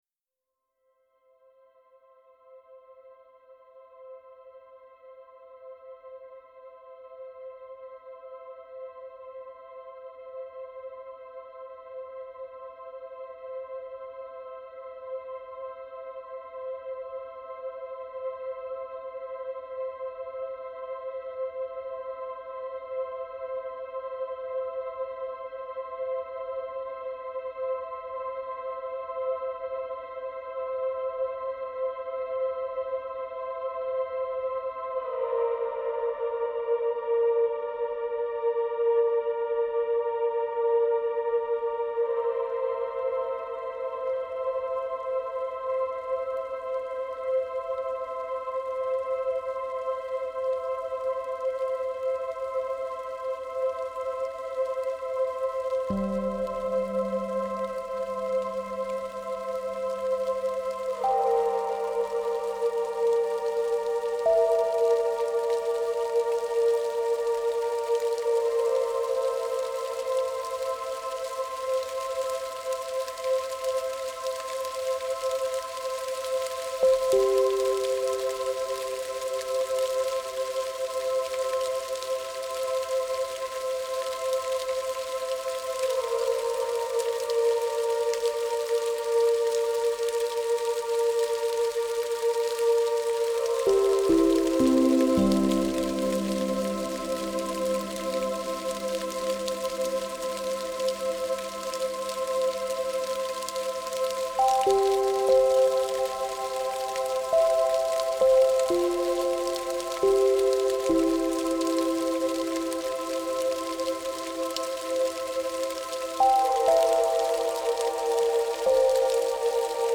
deep ambient
using a blend of synthesized sounds and field recordings.